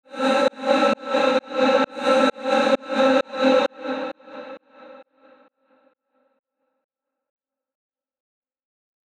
Download Reverse sound effect for free.